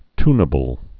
(tnə-bəl, ty-)